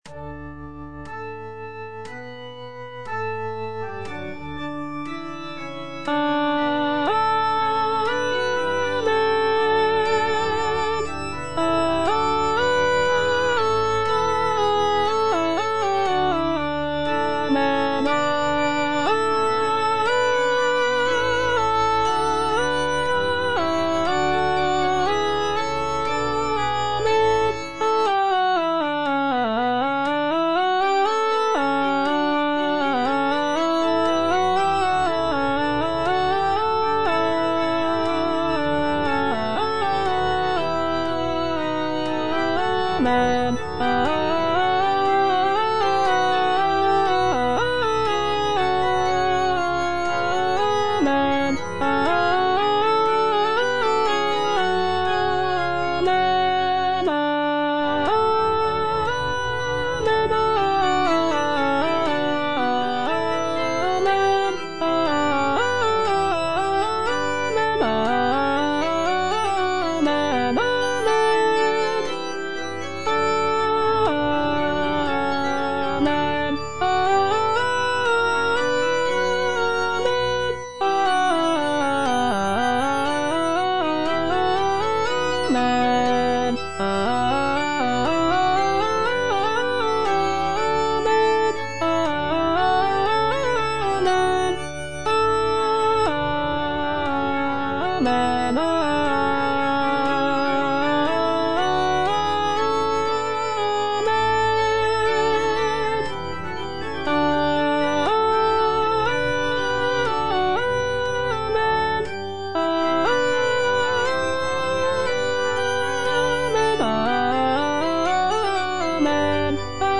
J.D. ZELENKA - MAGNIFICAT IN D MAJOR ZWV108 Amen - Alto (Voice with metronome) Ads stop: auto-stop
The composition showcases Zelenka's remarkable contrapuntal skills, with intricate vocal lines and rich harmonies.